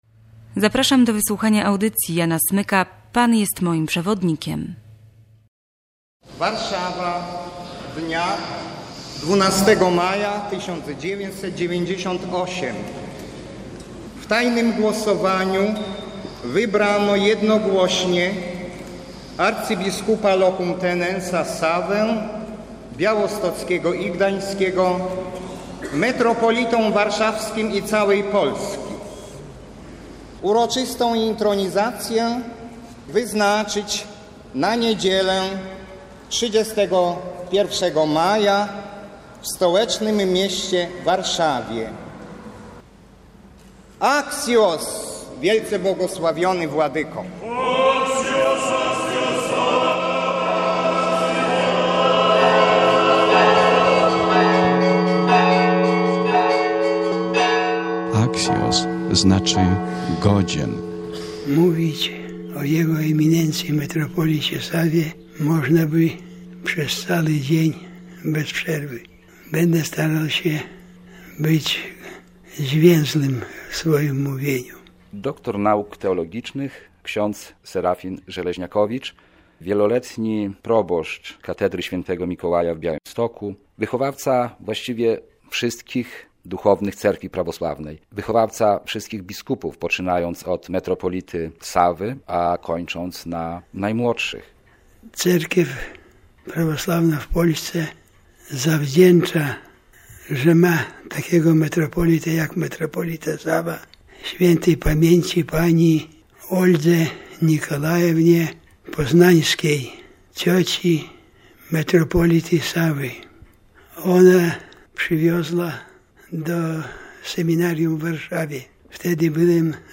Dźwiękowy portret metropolity Sawy Hrycuniaka